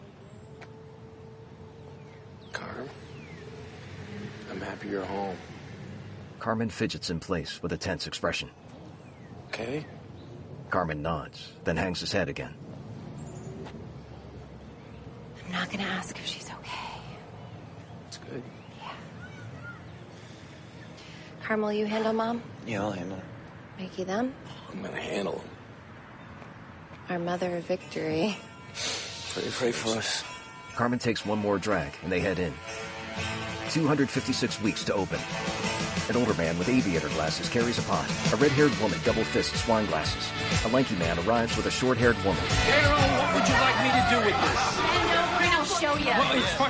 Audio Description Sample